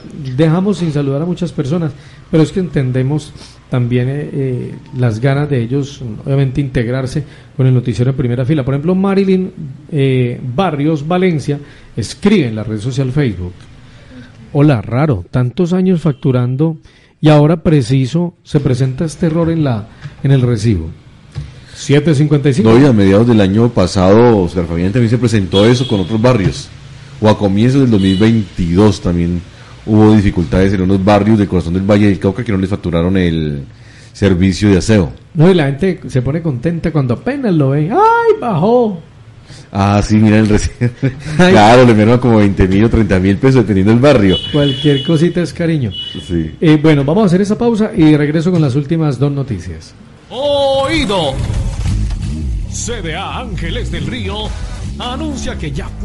Radio
Oyentes comentan acerca de este error.